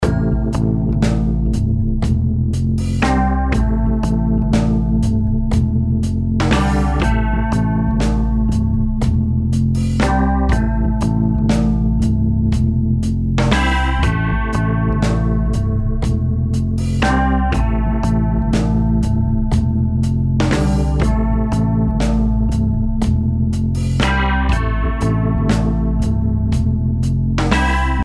Zur Klangerzeugung werden Stahlplättchen mit einem gummibeschichteten Hammer angeschlagen.
Der in Präzisionsarbeit wieder auferstandene Klassiker verfügt über eine nuancierte Anschlagsdynamik und liefert bei sanftem Anschlag weiche Klänge, während er hart gespielt bissig und rockig klingt – mit fließendem Übergang, versteht sich.
Den Timbre Shift – Regler habe ich moduliert: